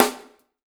LG SNR 2  -R.wav